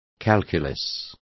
Complete with pronunciation of the translation of calculi.